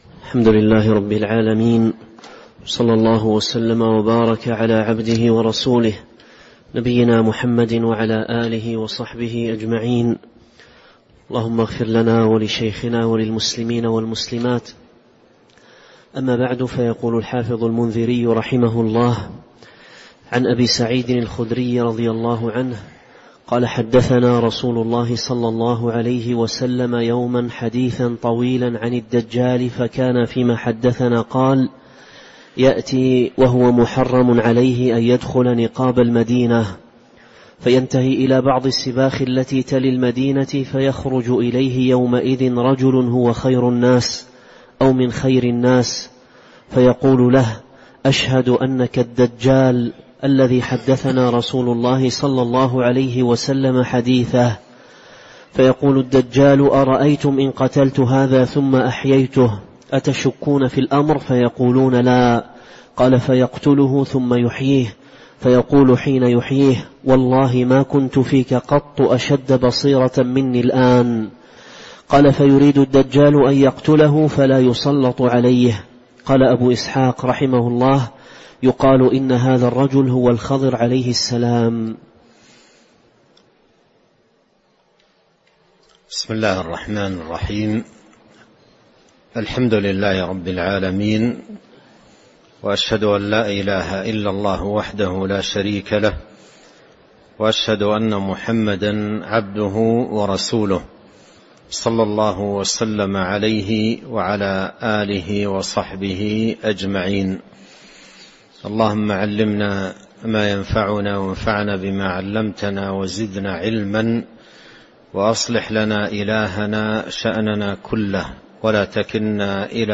تاريخ النشر ١٥ صفر ١٤٤٤ هـ المكان: المسجد النبوي الشيخ